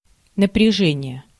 Ääntäminen
IPA: /nəprʲɪˈʐɛnʲɪjə/